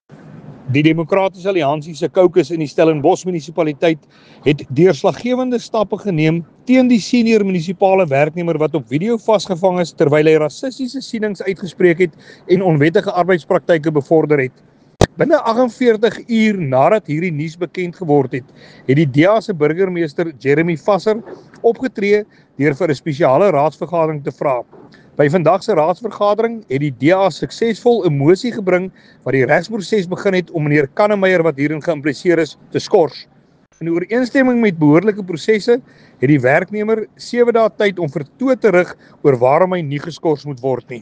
English soundbite by Willie Aucamp MP.